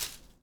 SFX_paso4.wav